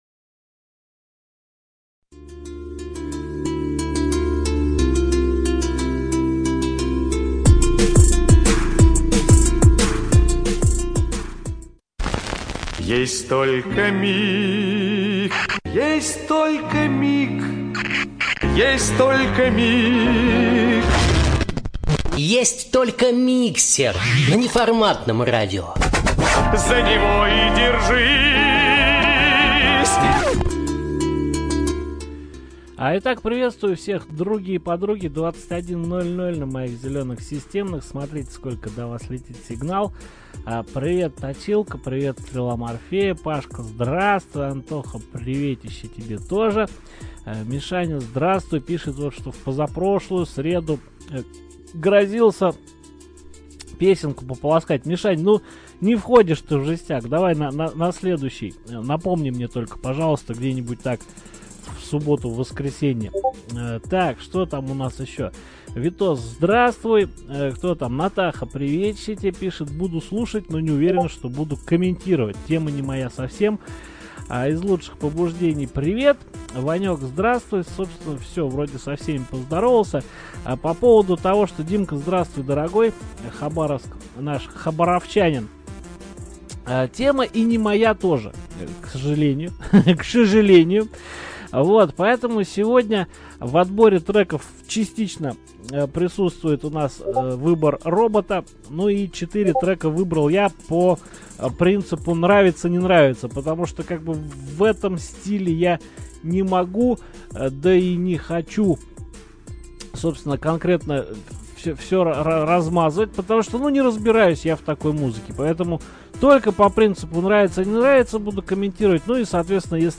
На практике Миксер получился не столько тяжёлым, сколько утяжелённым, но послушать было что.